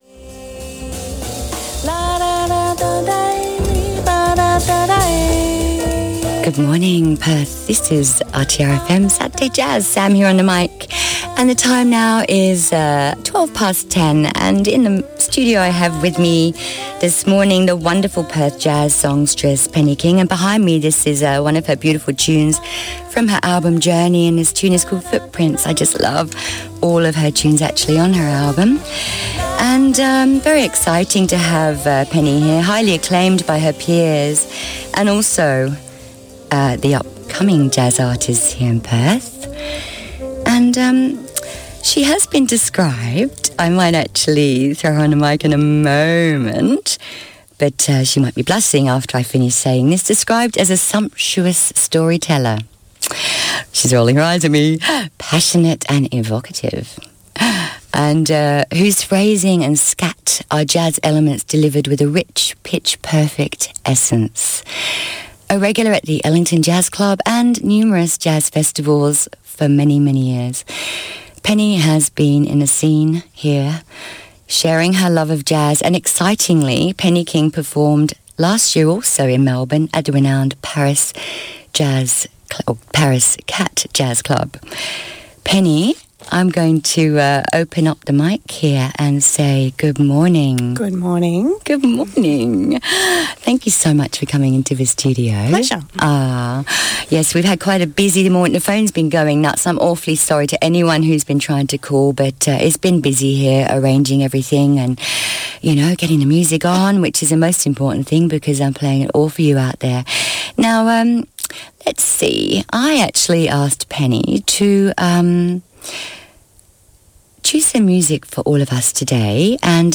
interview.wav